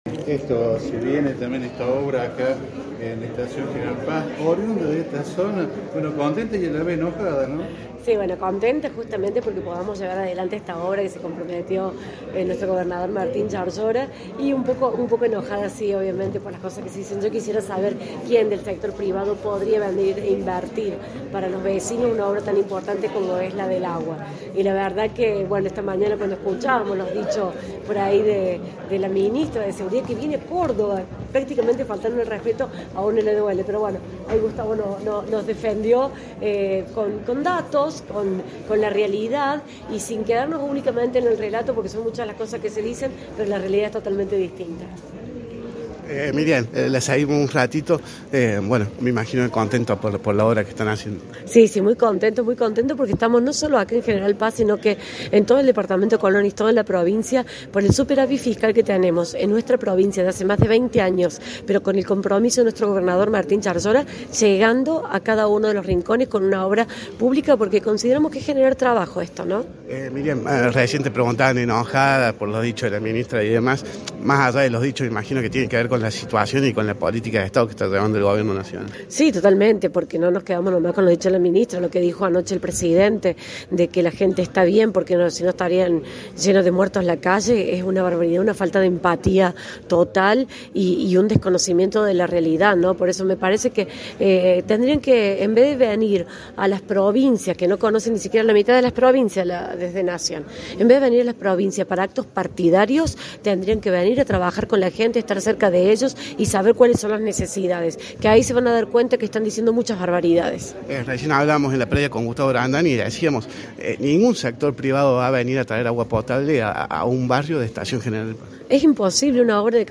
La ceremonia fue pasado el mediodía en las instalaciones del Cine Teatro Municipal.
ENTREVISTA A MYRIAN PRUNOTTO, VICEGOBERNADORA DE CÓRDOBA